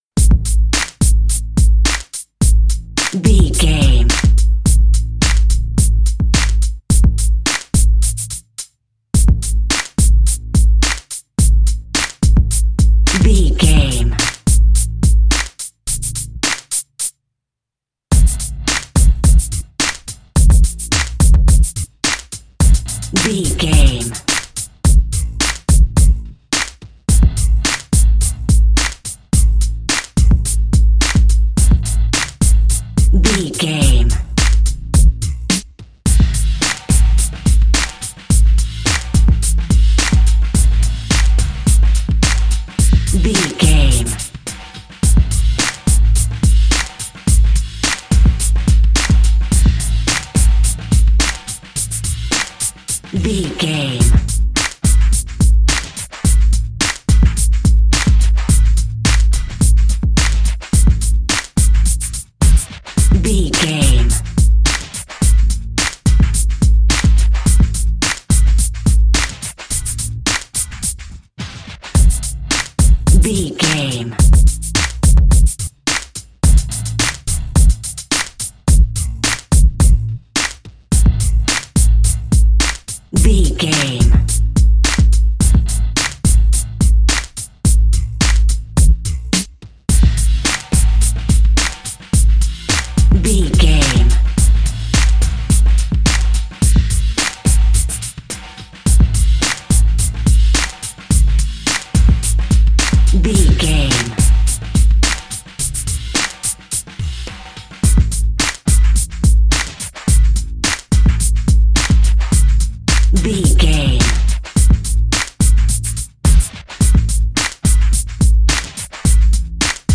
Aeolian/Minor
drum machine
synthesiser
drums
hip hop
Funk
neo soul
acid jazz
energetic
bouncy
Triumphant
funky